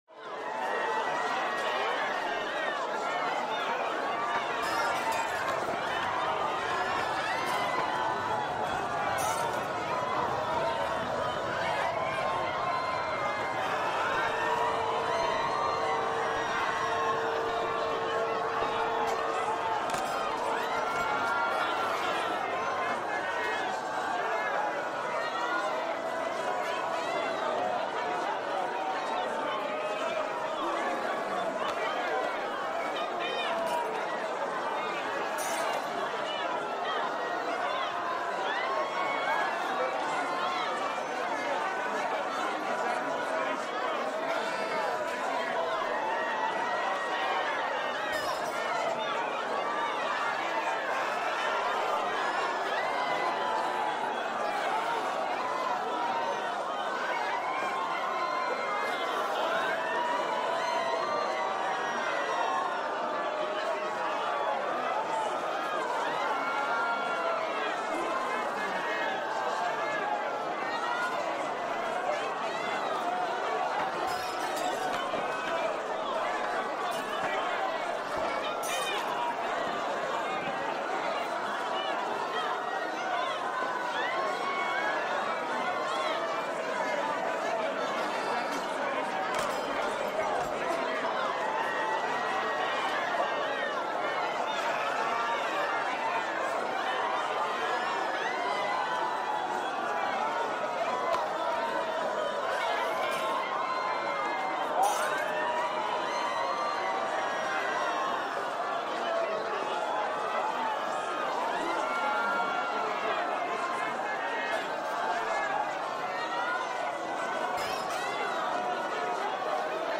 Шум перепуганной толпы